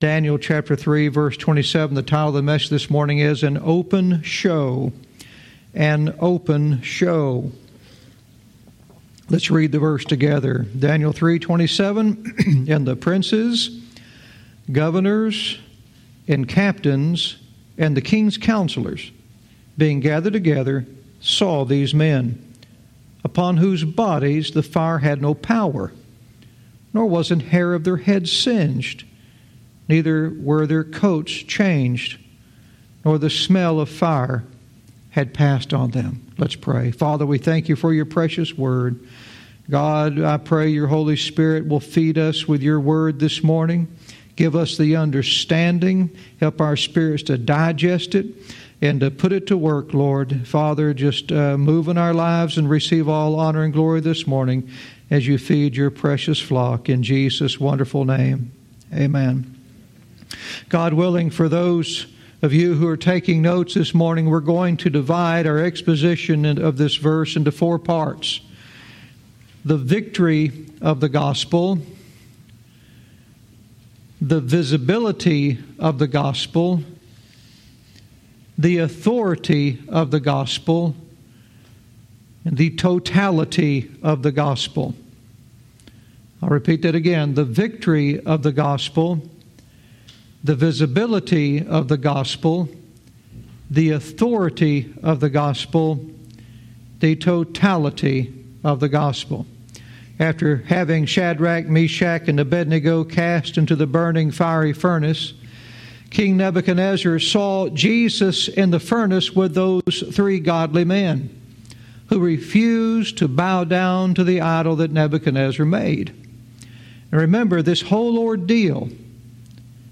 Verse by verse teaching - Daniel 3:27 "An Open Show"